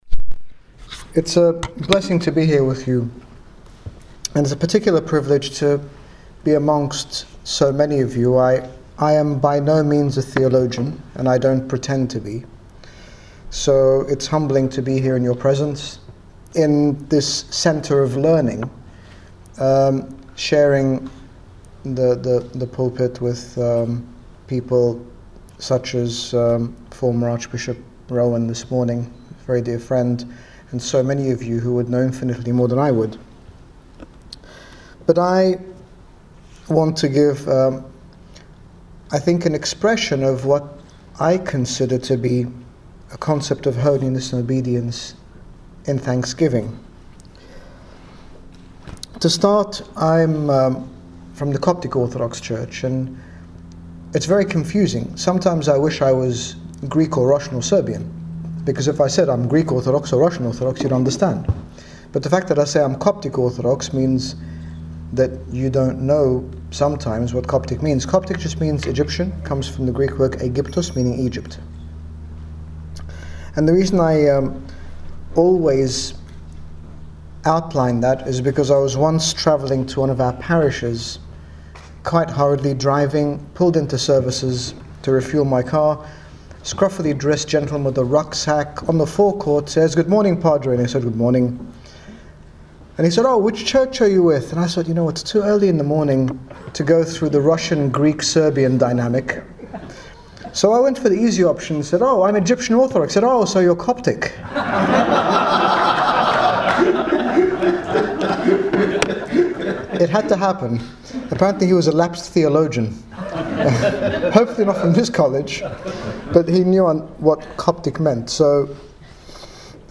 In this talk given at the 19th International ESBVM Congress 2014, His Grace Bishop Angaelos speaks about the benefits of obedience and thanksgiving, using the life of Saint Mary as an example of achievable holiness. Download Audio Read more about The holiness of obedience and thanksgiving